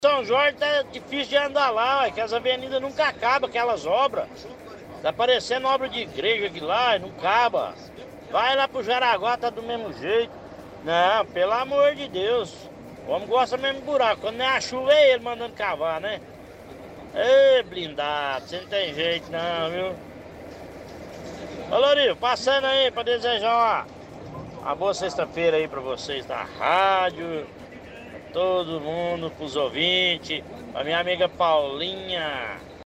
– Ouvinte reclama do tempo de duração de obras pela cidade.